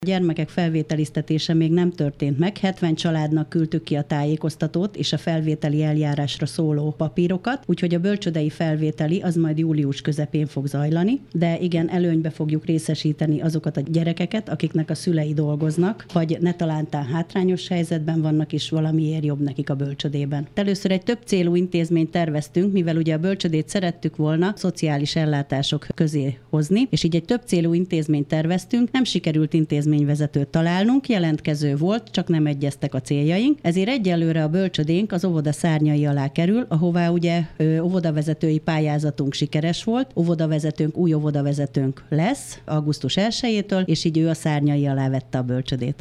A több ezer látogatót vonzó látványosság kiterjedt szervezői körrel rendelkezik, akik megkezdték a tárgyalásokat az esetleges lebonyolításról. Dr. Tüske Zoltán polgármestert hallják.